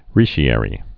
(rēshē-ĕrē)